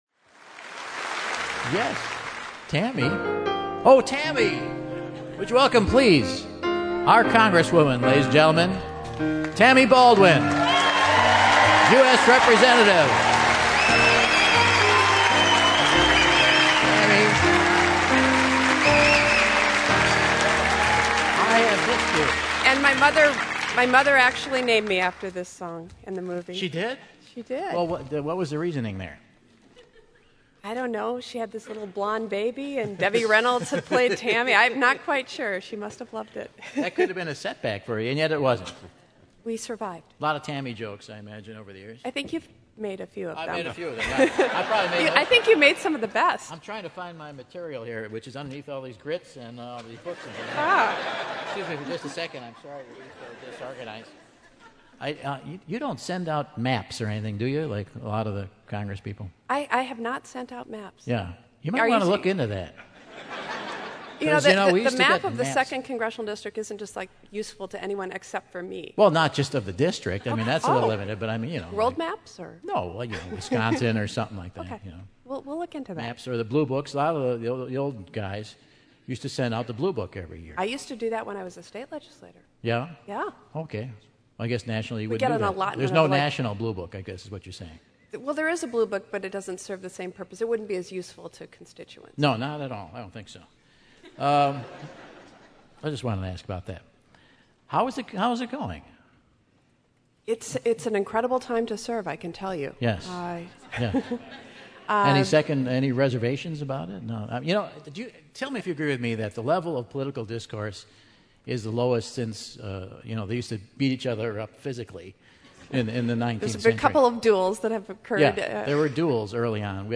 Before she made her successful bid for the Senate, US Congresswoman Tammy Baldwin graced the WYK stage for Michael's 25th jubilee to hear some of his "Tammy" jokes!